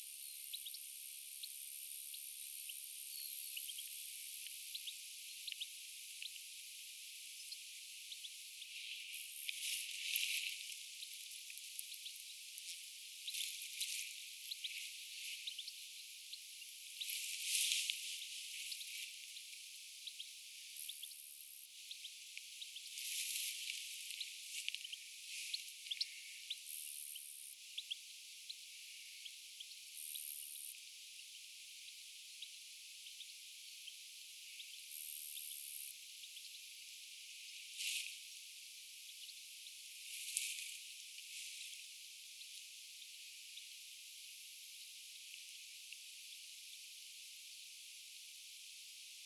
kaksi tikliä lentää ympärillä
kaksi_tiklia_lentaa_ymparilla_hyonteispaikalla_tiklien_aania_lennossa.mp3